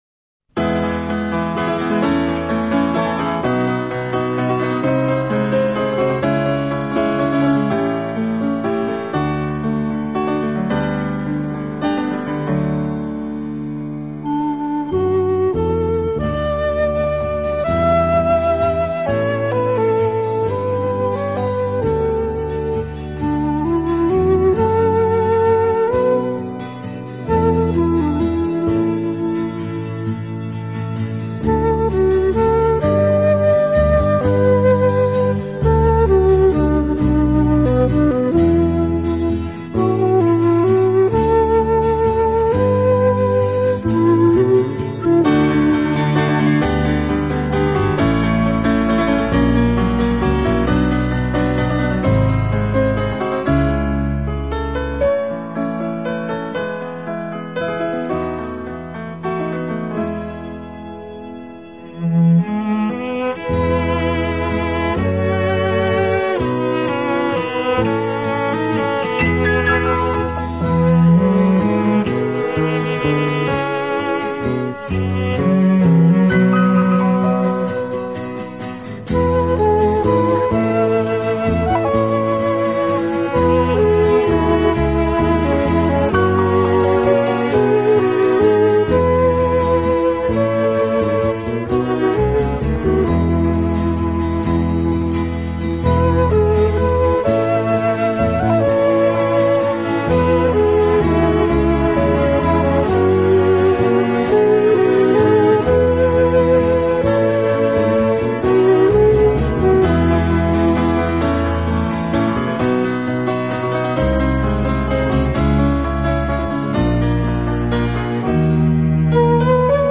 大多轻拉轻弹轻敲，给人安宁、清静、舒适的感觉。
温柔、宁静、娓娓动听。
是乐队演奏的主要乐器，配以轻盈的打击乐，使浪漫气息更加浓厚。